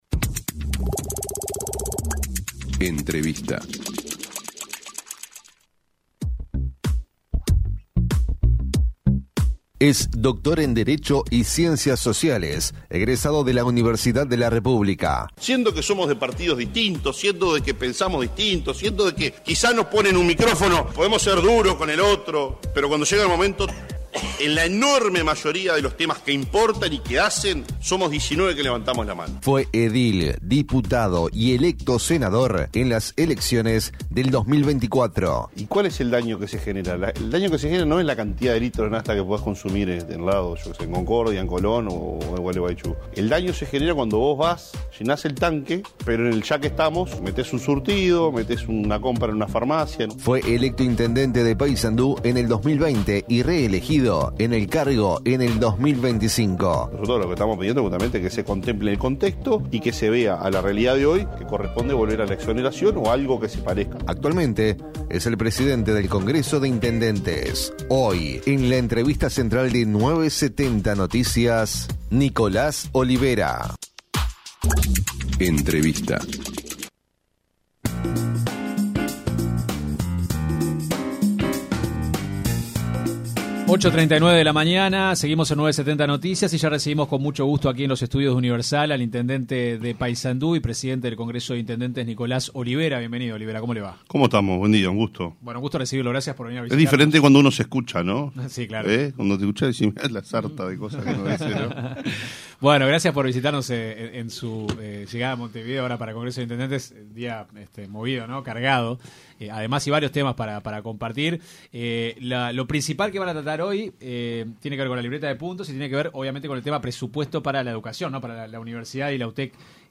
El intendente de Paysandú, Nicolás Olivera se refirió en diálogo con 970 Noticias al proyecto de hidrógeno verde que se plantea en el departamento. Explicó de qué trata la iniciativa y remarcó la importancia que tiene para nuestro país.